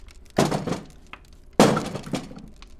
metal thud